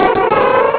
Cri de Lumivole dans Pokémon Rubis et Saphir.